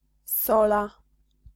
Ääntäminen
Synonyymit emblavure Ääntäminen France: IPA: /sɔl/ Haettu sana löytyi näillä lähdekielillä: ranska Käännös Ääninäyte Substantiivit 1. sola {f} Suku: f .